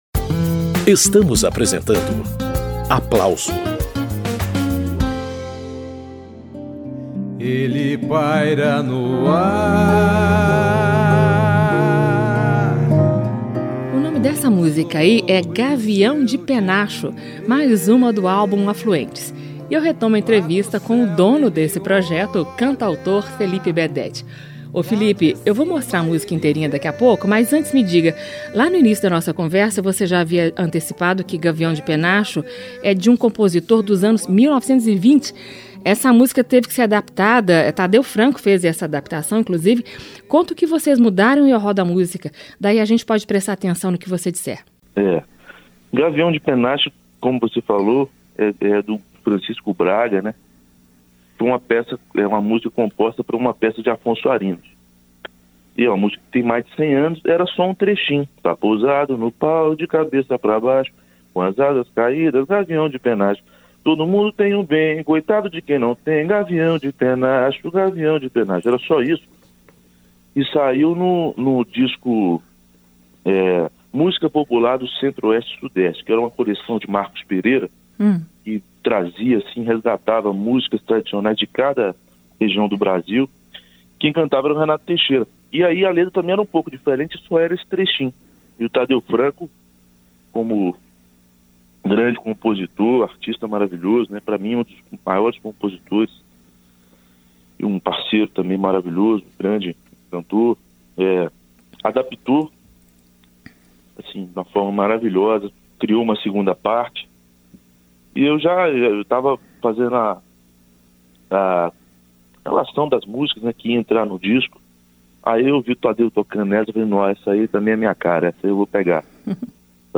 aposta no diálogo entre valsa, choro, xote e jazz
O álbum é resultado de uma rede colaborativa formada por músicos de várias partes do Brasil que se organizaram para gravações à distância, durante a pandemia.